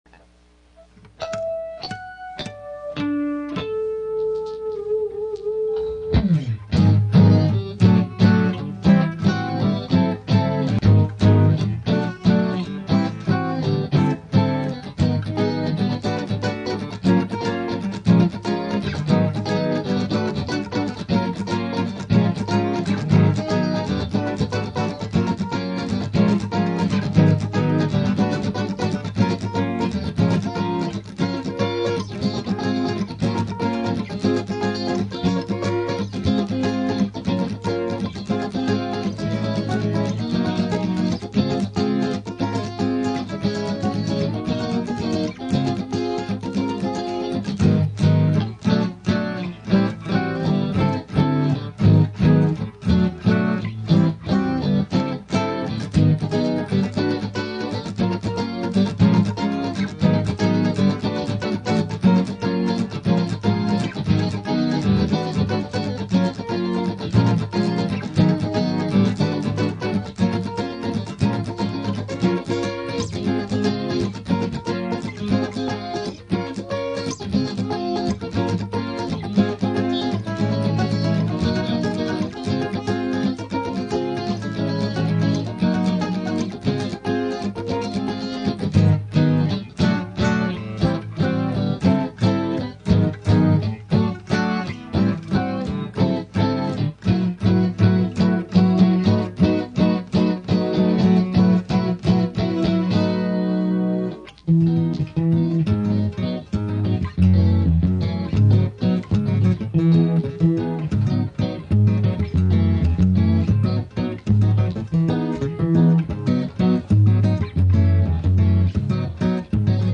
Instrumentals
A jazzy little number. Instrumental Jazz 1,569 KB 3:49